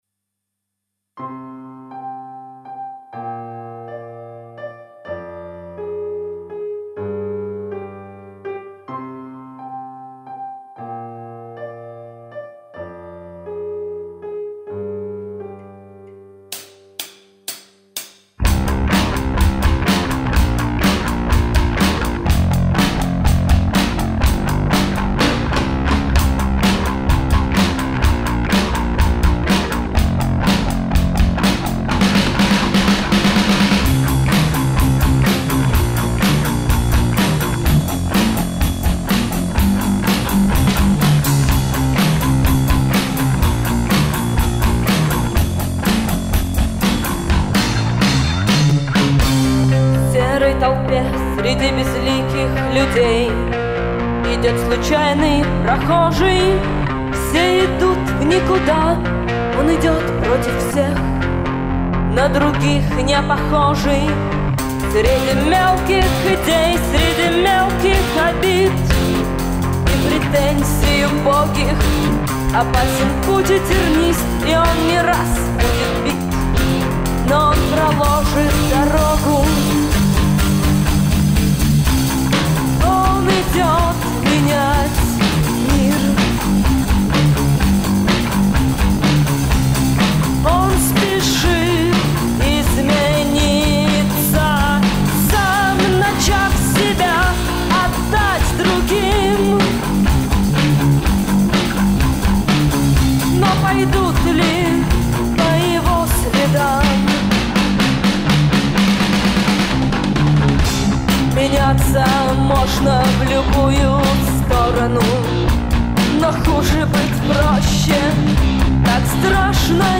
рок-группы
Achtung!!! 100% живой звук!